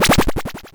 sfx_dead.mp3